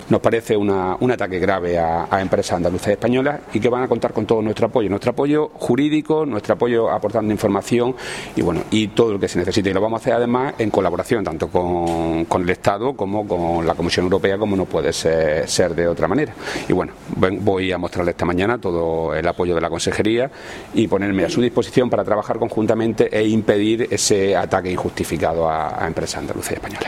Declaraciones consejero apoyo al sector